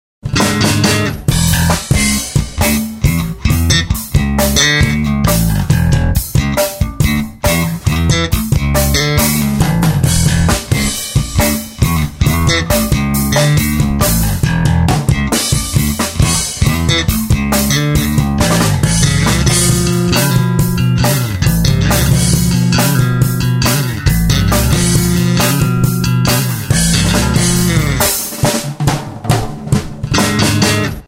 Бас-гитара Alina Pro JazzMaster M
1. JazzMaster M в линию 485,71 Кб
alina_pro_jazzmaster_m.mp3